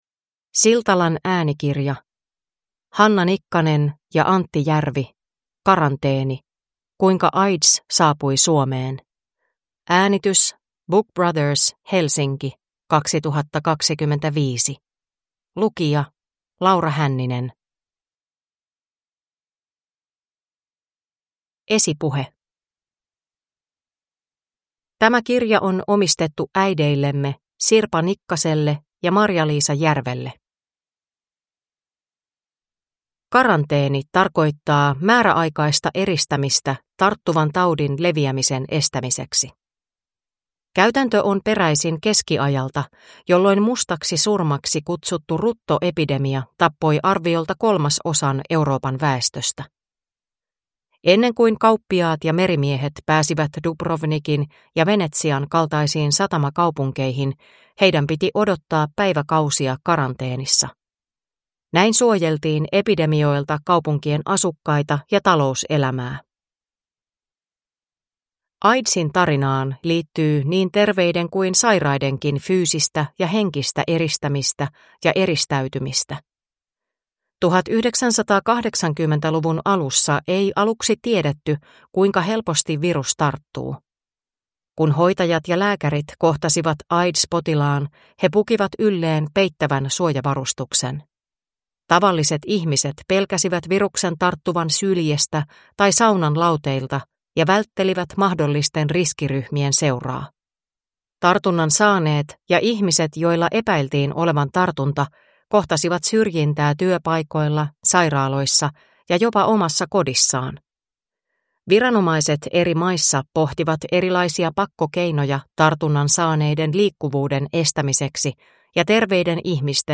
Karanteeni (ljudbok) av Hanna Nikkanen